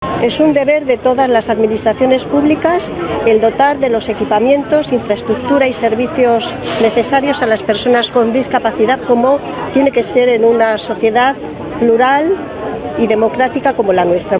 El acto se ha desarrollado en la Avenida Federico García Lorca de la capital y ha contado con el conjunto de entidades de Almería que representan a las personas con discapacidad.